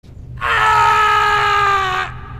Play, download and share Grito Mais Fera de Todos original sound button!!!!
grito-2_pb06QdG.mp3